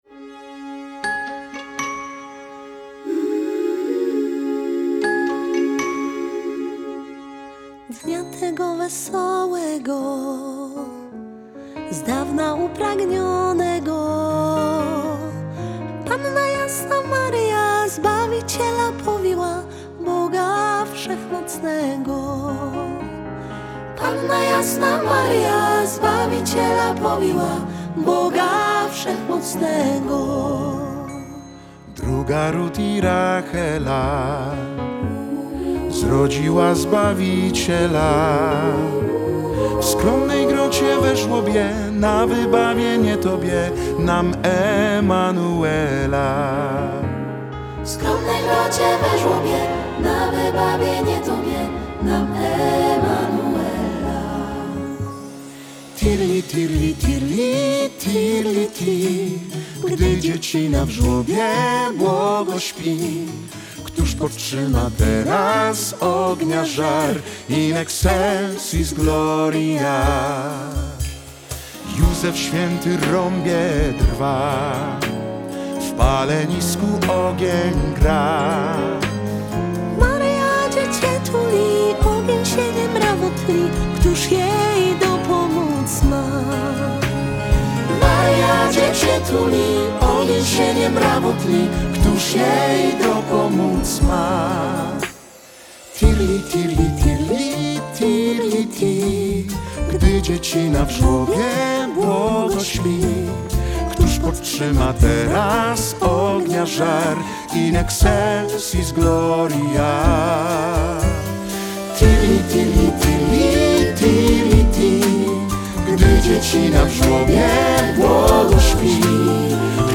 Singiel (Radio)
to nastrojowy i pełen ciepła utwór